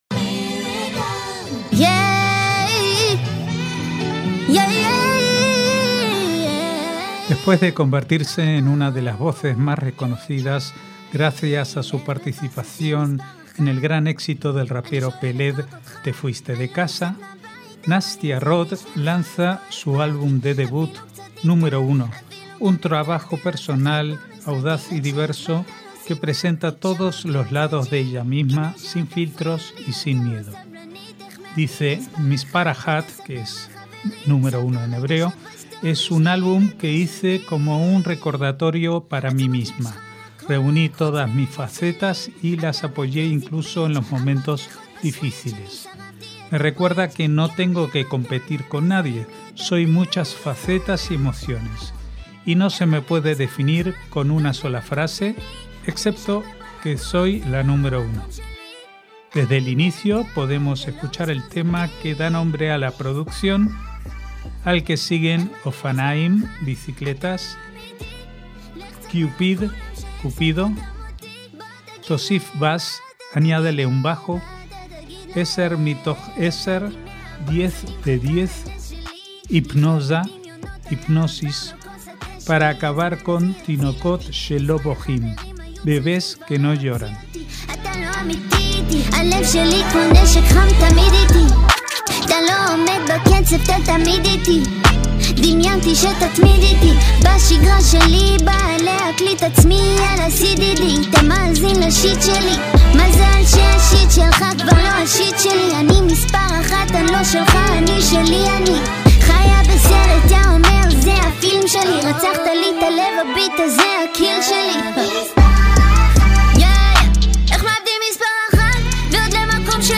MÚSICA ISRAELÍ
un trabajo personal, audaz y diverso